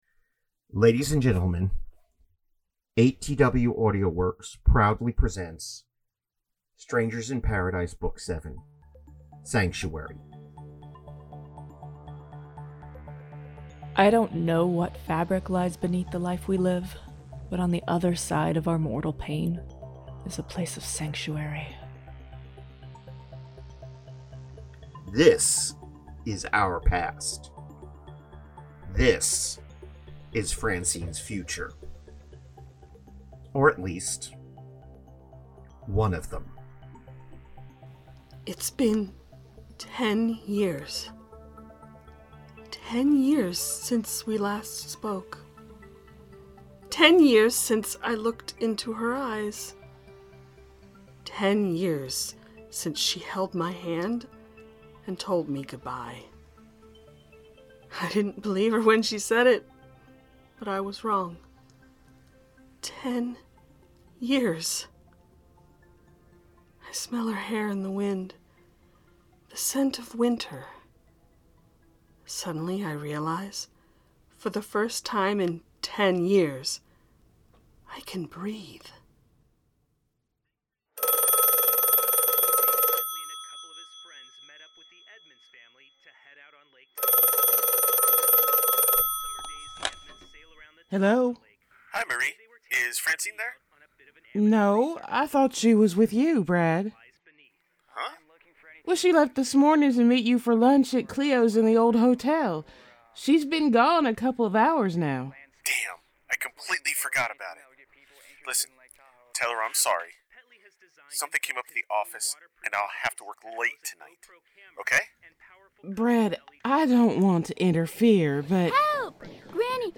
The Ocadecagonagon Theater Group